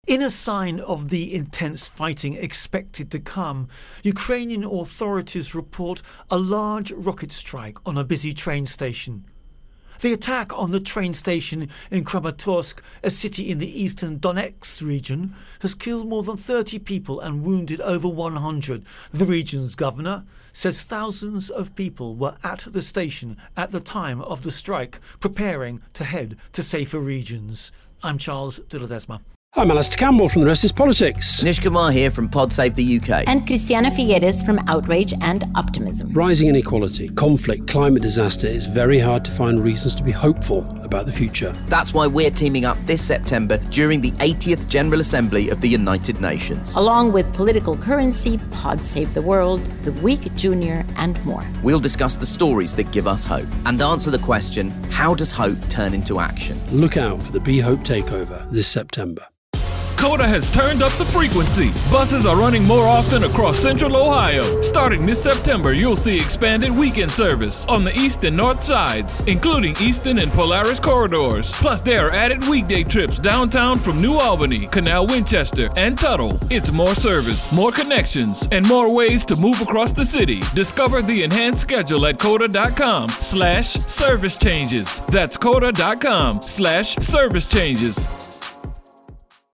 Russia-Ukraine-War-Station Attack Intro and Voicer